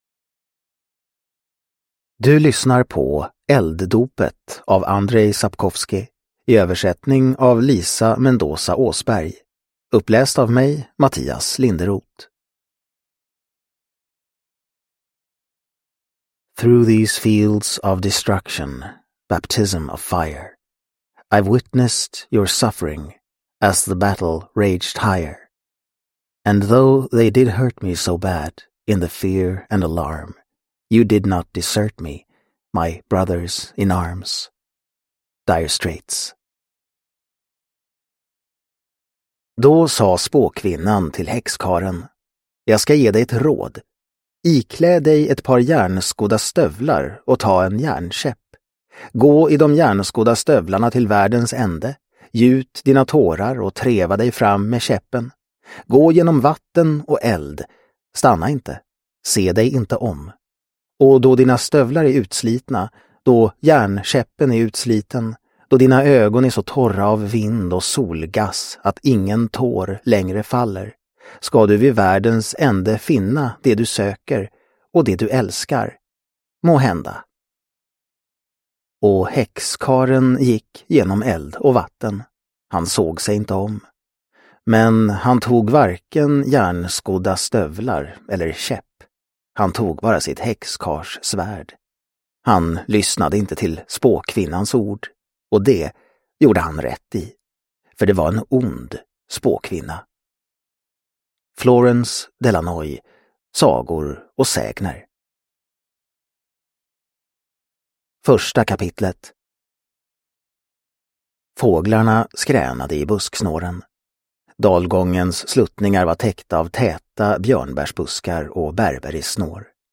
Elddopet – Ljudbok – Laddas ner